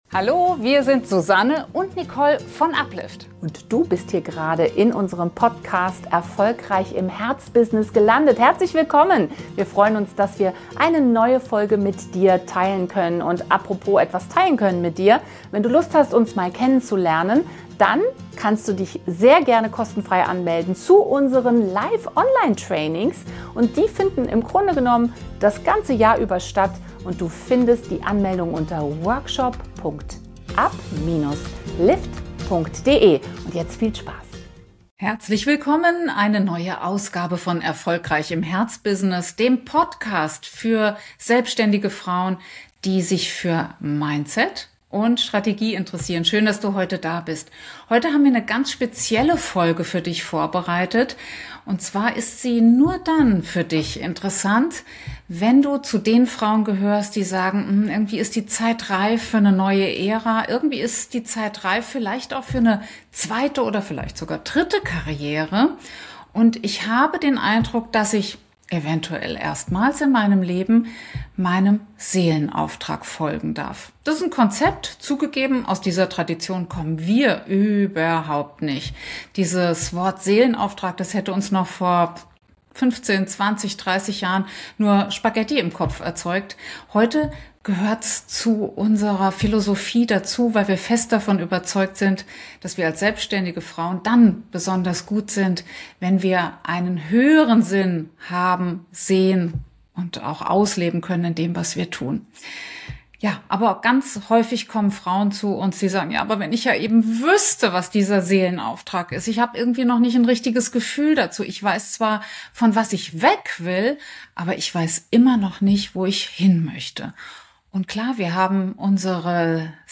In dieser besonderen Podcastfolge laden wir Dich zu einer geführten Med...
In dieser besonderen Podcastfolge laden wir Dich zu einer geführten Meditation ein. Einer inneren Reise in die Weite der Wüste.